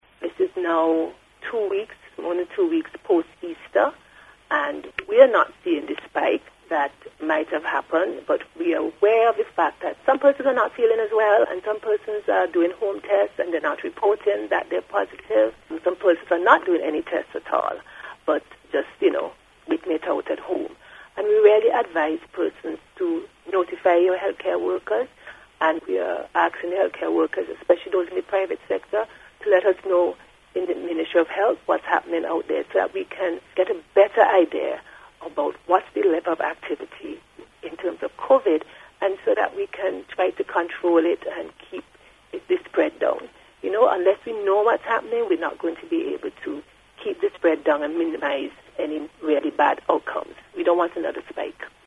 In an interview with NBC News, the CMO said as of yesterday the country had twenty-one active cases of COVID19.